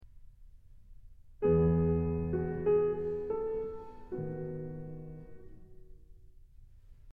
In fact, just by playing the very first chord, the music signals a complete change: